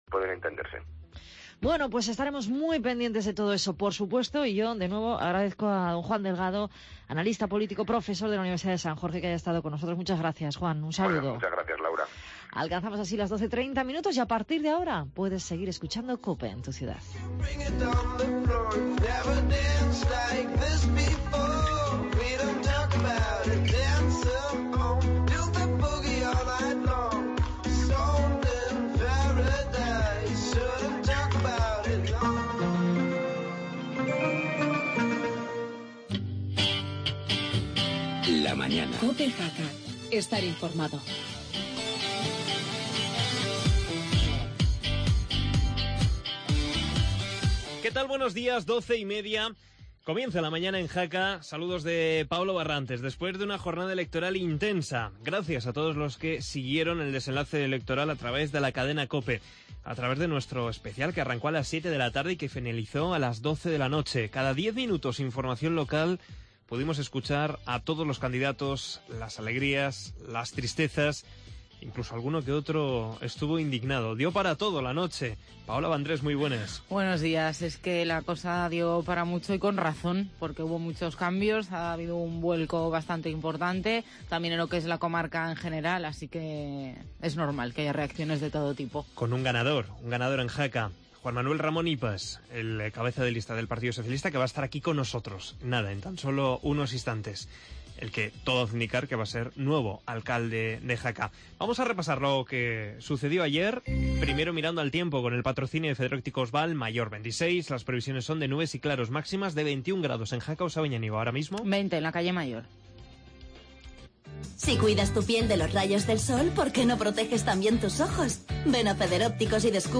AUDIO: Actualidad post electoral y entrevista al ganador de las elecciones municipales de Jaca Juan Manuel Ramón Ipas (PSOE).